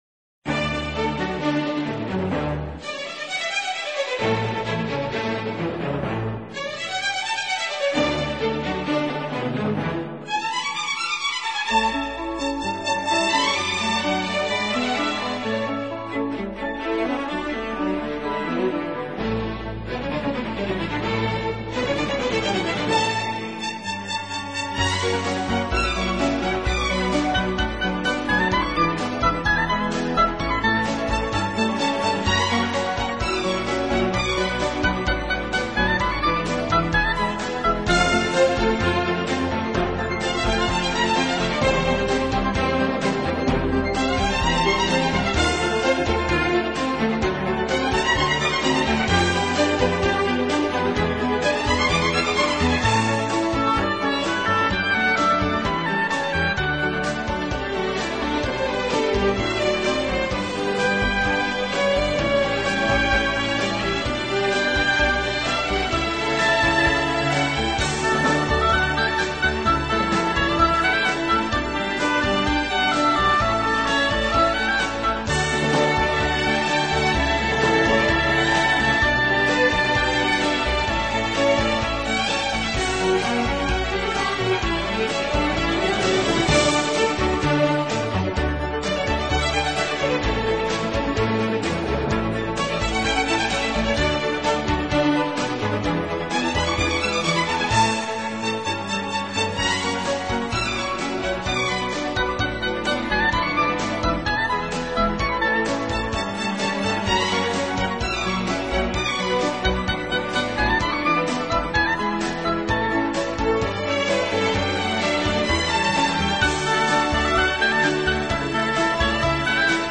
音乐类型：跨界融合 Fusion
音乐风格：Neo Classical，室内乐